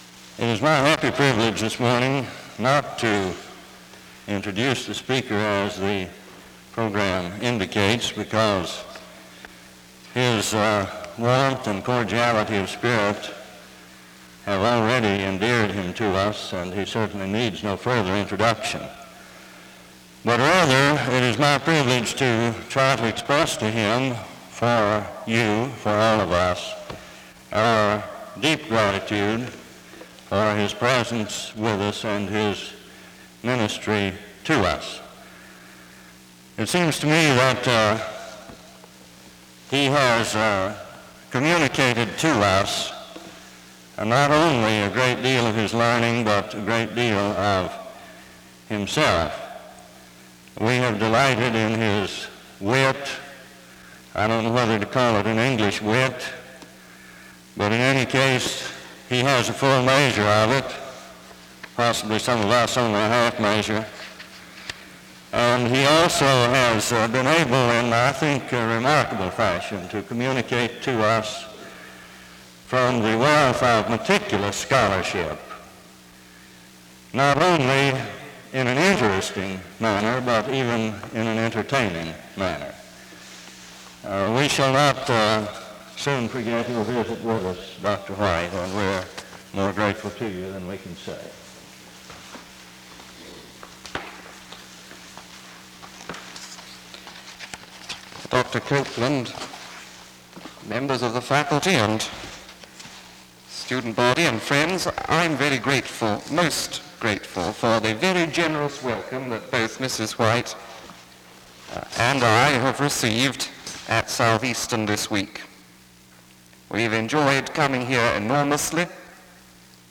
The service opens with an introduction to the speaker from 0:00-1:31.
Chapel and Special Event Recordings